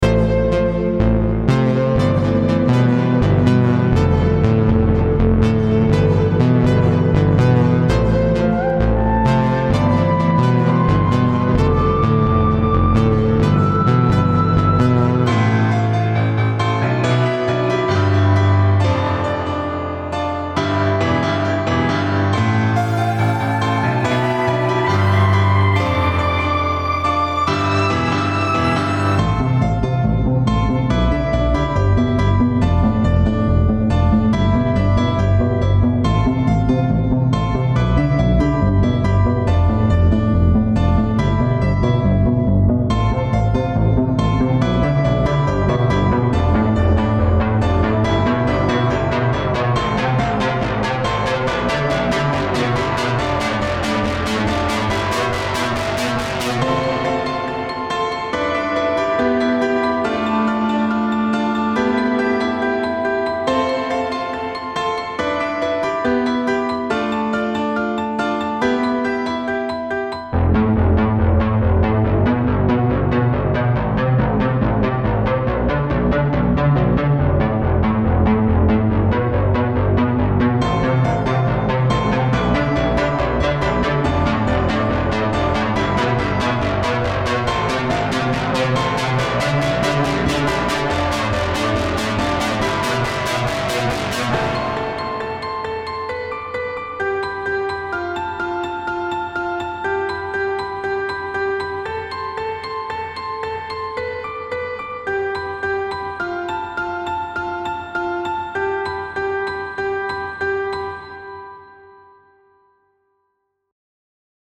Home > Music > Electronic > Running > Chasing > Restless